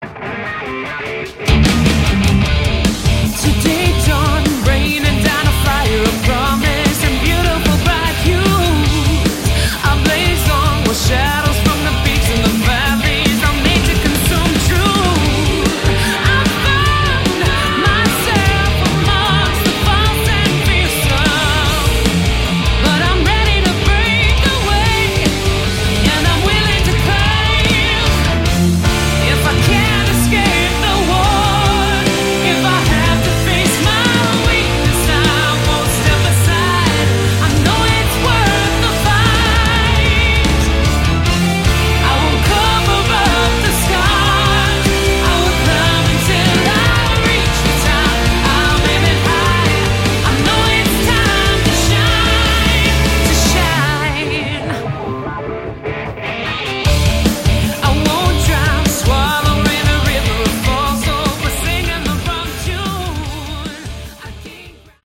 Category: Melodic Metal